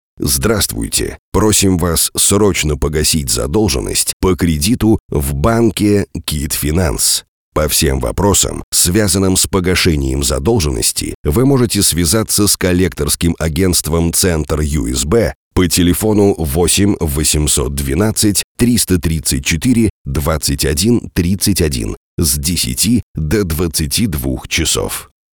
Автоответчик